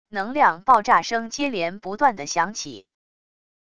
能量爆炸声接连不断的响起wav音频